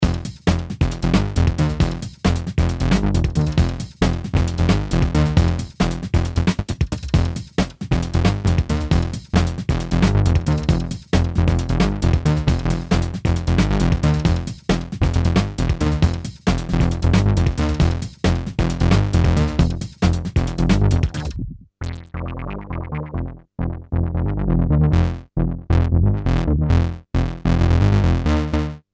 Arp Odyssey
tailgunner demo